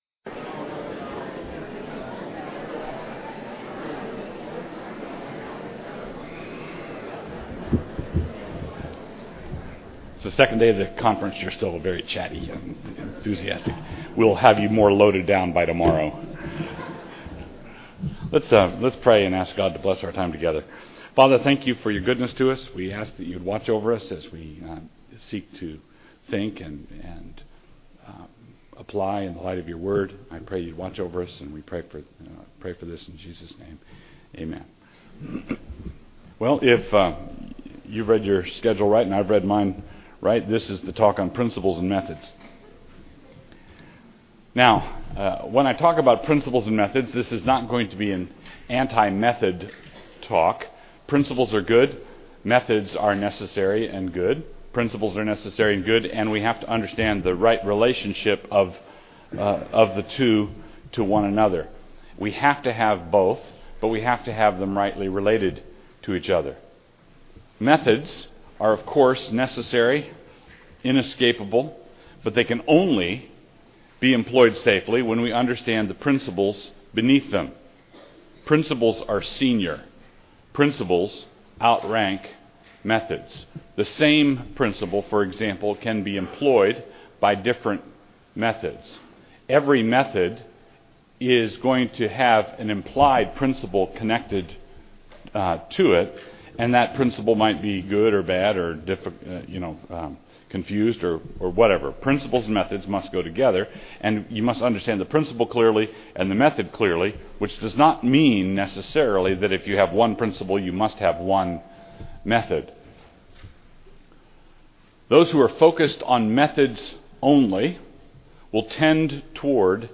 2009 Workshop Talk | 1:01:22 | All Grade Levels, Leadership & Strategic, General Classroom